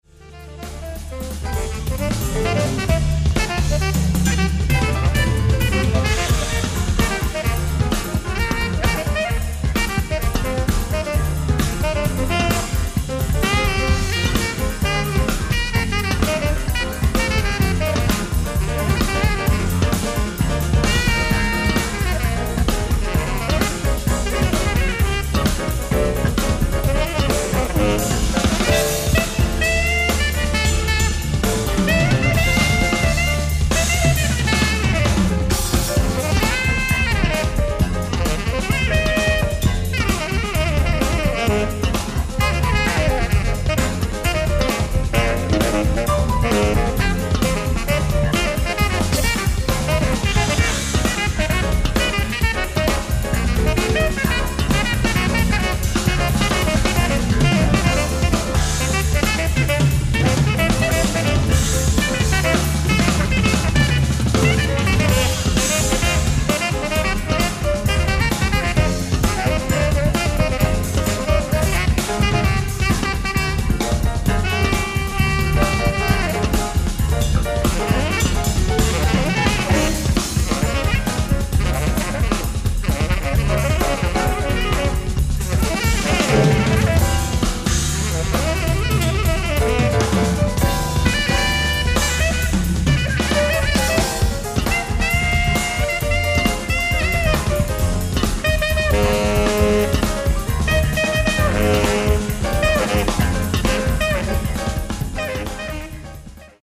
ライブ・アット・ロイヤル・コンサートホール、グラスゴー、スコットランド 07/01/1991
音源の劣化を感じさせないリマスタリング！！
※試聴用に実際より音質を落としています。